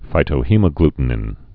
(fītō-hēmə-gltn-ĭn)